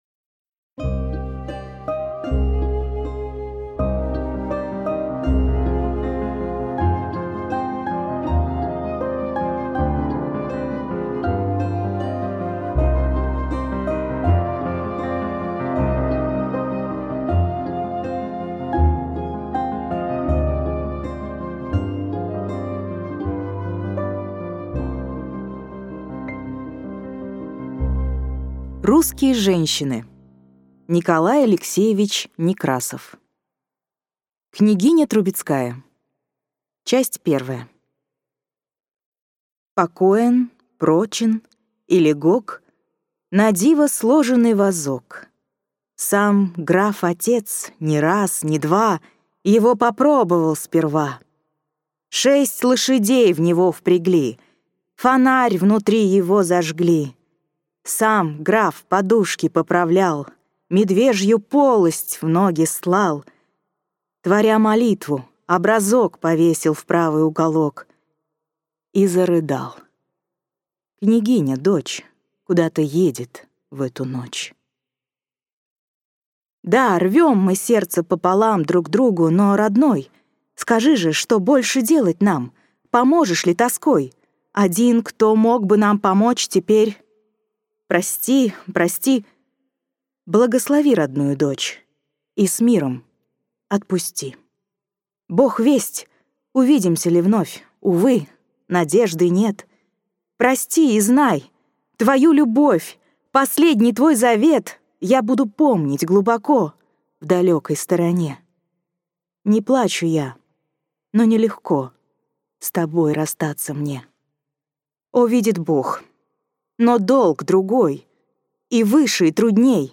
Аудиокнига Русские женщины | Библиотека аудиокниг